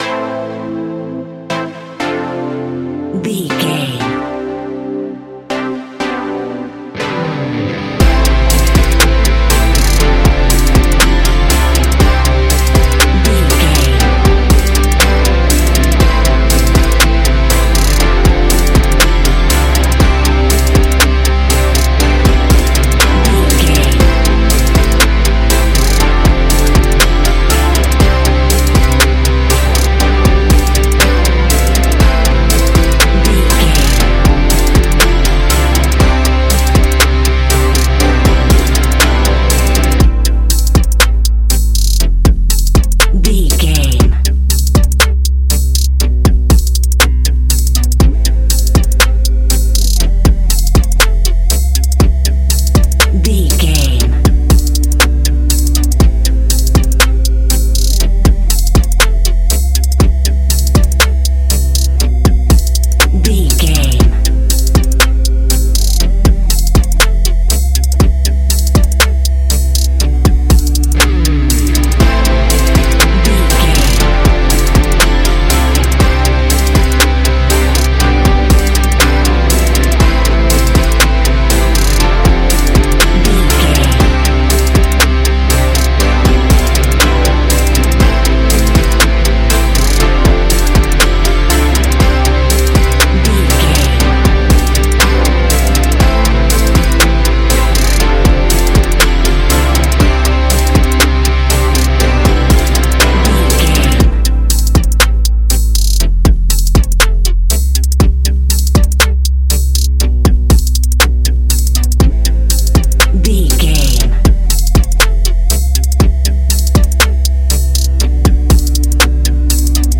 Ionian/Major
ambient
electronic
new age
chill out
downtempo
soundscape
synth
pads
drone
instrumentals